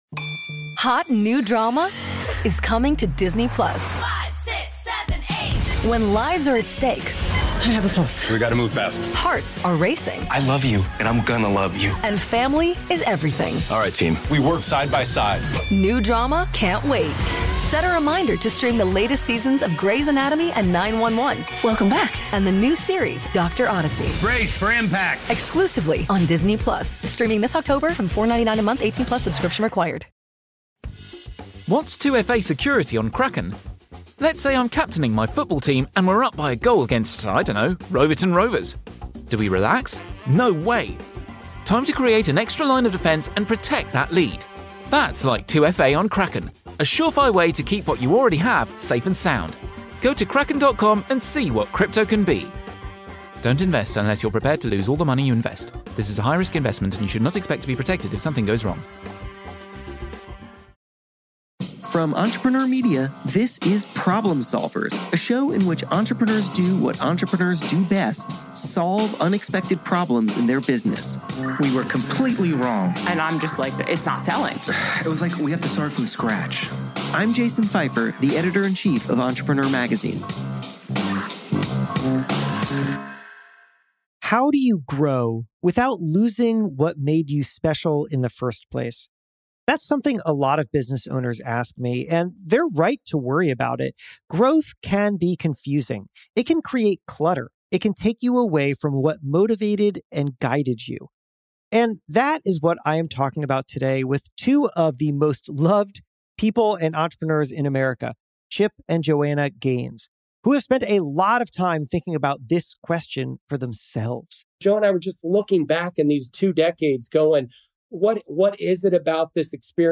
In this episode, a candid conversation with them about growth, challenges, and what should always remain the same.